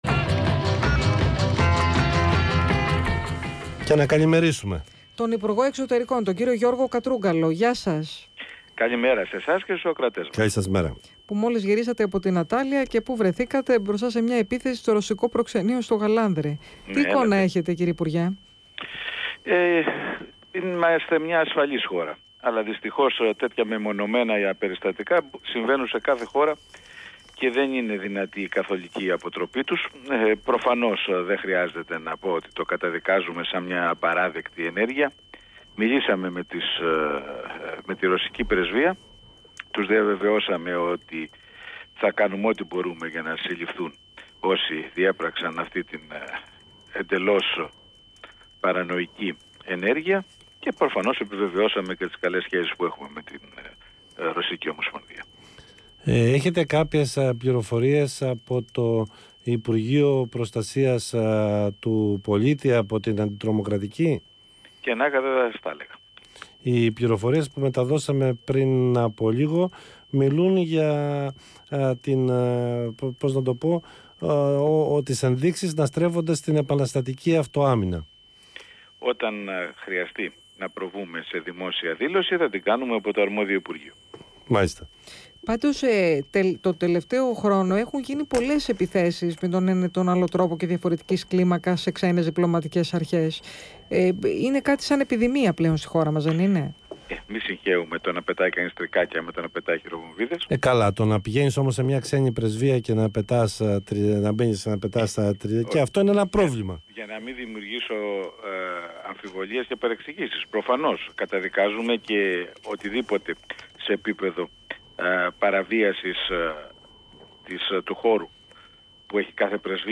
Συνέντευξη Υπουργού Εξωτερικών